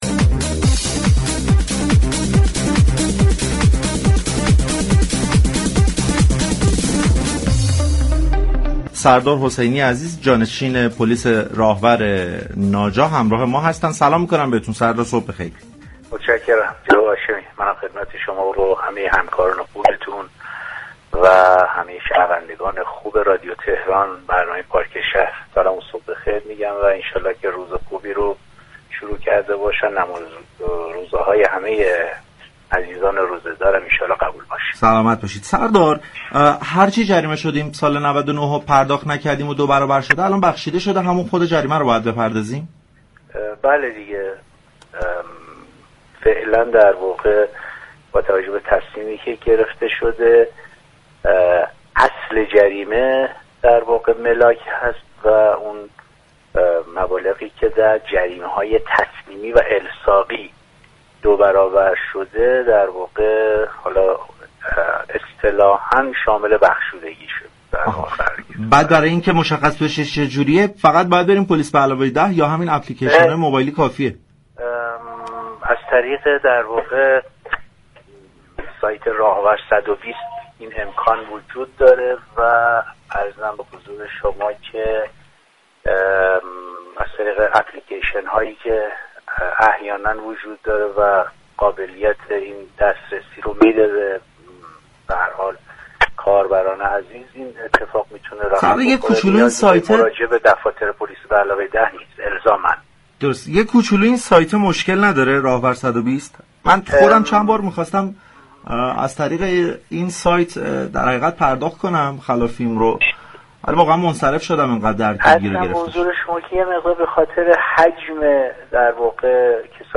به گزارش پایگاه اطلاع رسانی رادیو تهران، سردار سیدتیمورحسینی جانشین پلیس راهور ناجا در گفتگو با برنامه پارك شهر 7 اردیبهشت درباره بخشودگی مبالغ دوبرابری جریمه رانندگی گفت: با توجه به تصمیم اخیر، اصل جریمه باید پرداخت شود و مبالغ جریمه های دوبرابری تسلیمی و الصاقی شامل بخشودگی شده است.